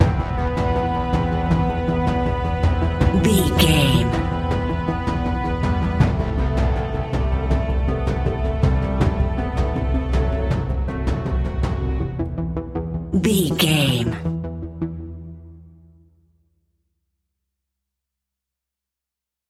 In-crescendo
Thriller
Aeolian/Minor
ominous
dark
eerie
synthesizer
percussion
instrumentals
horror music